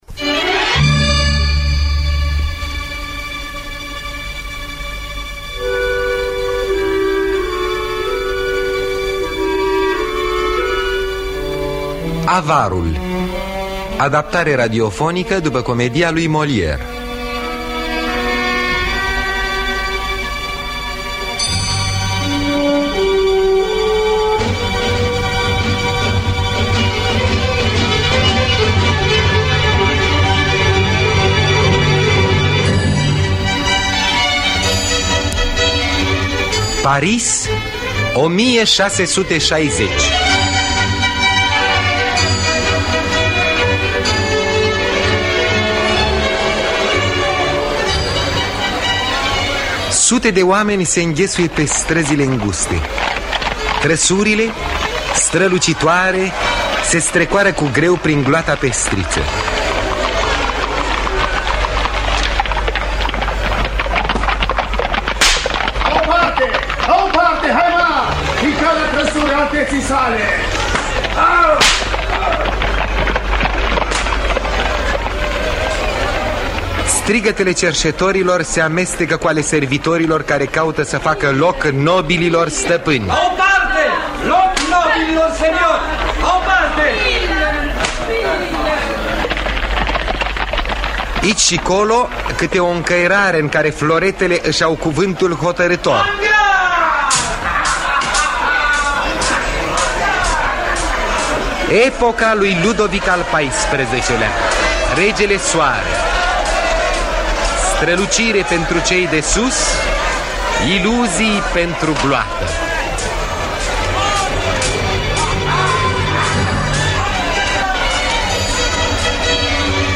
Avarul de Moliere – Teatru Radiofonic Online